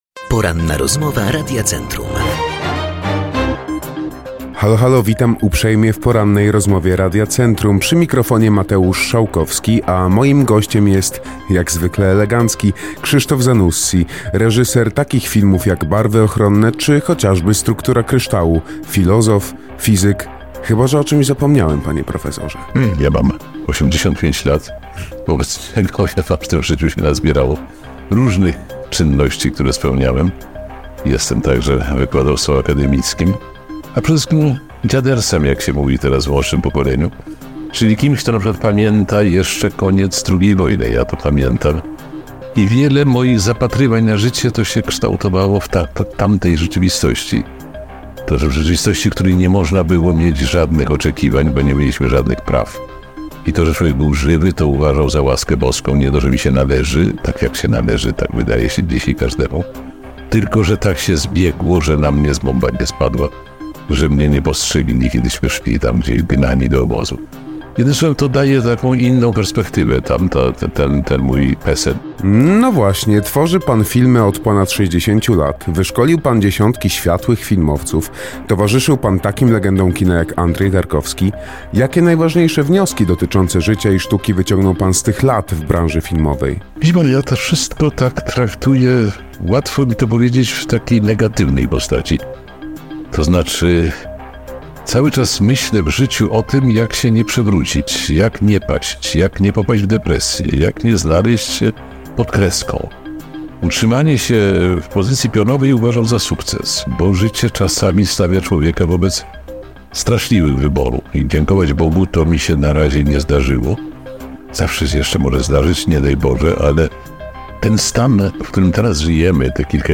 ROZMOWA-online-audio-converter.com_.mp3